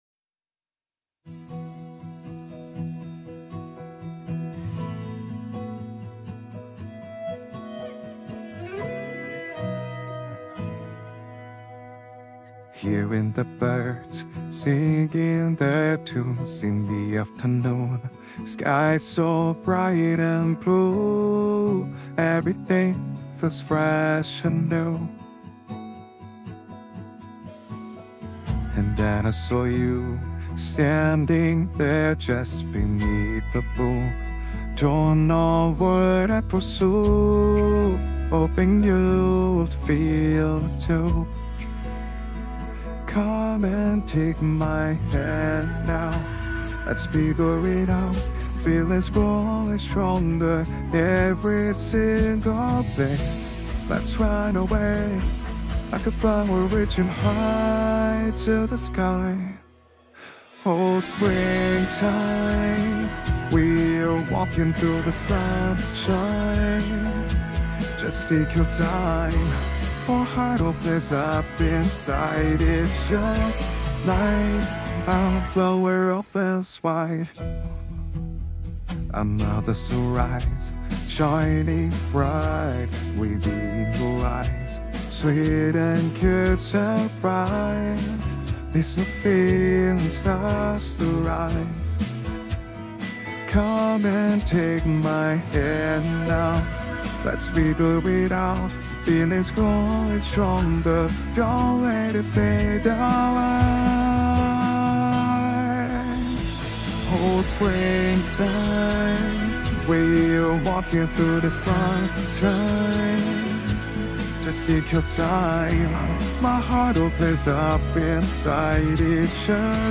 AI COVER
AI Cover Sing App Used